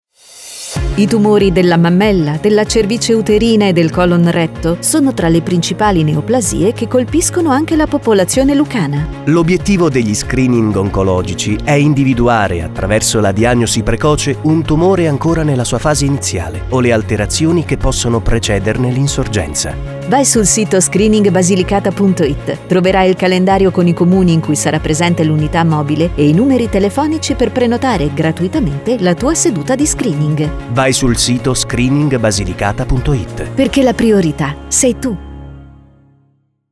spot audio 30″